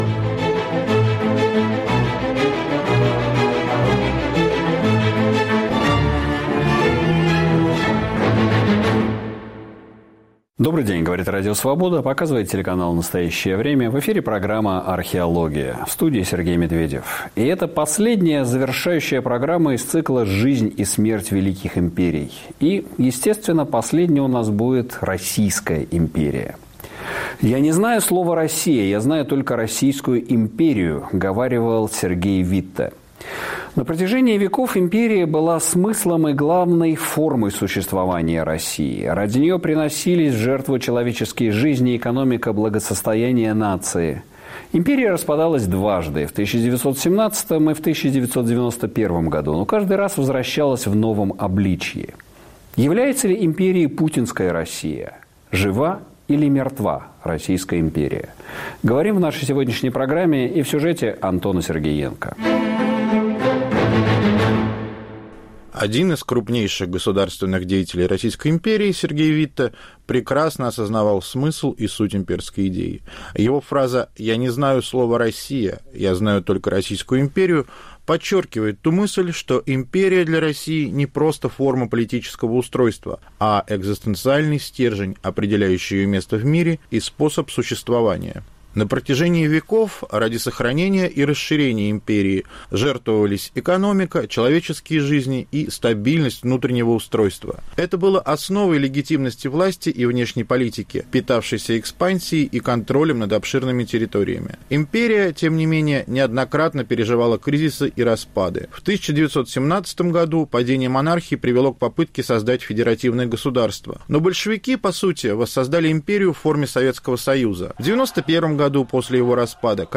Повтор эфира от 18 декабря 2024.